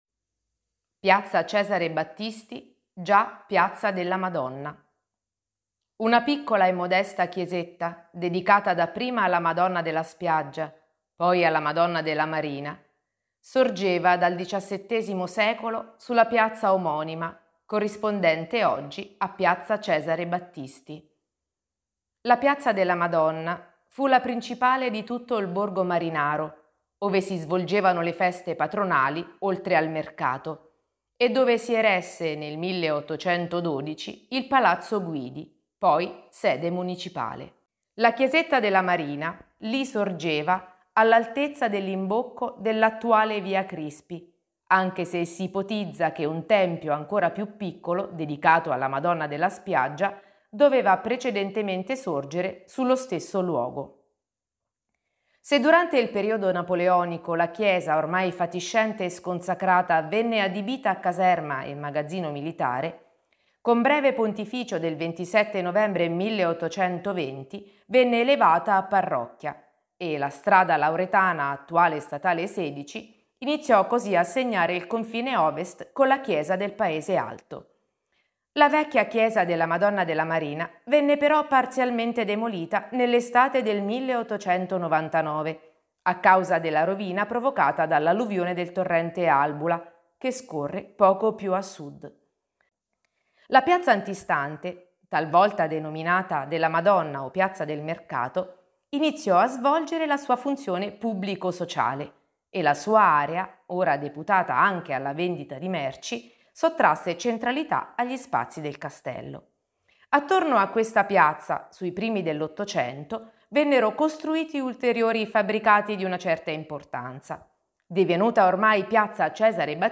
RIPRODUCI L'AUDIOGUIDA COMPLETA RIPRODUCI FERMA oppure LEGGI LA STORIA GUARDA IL VIDEO LIS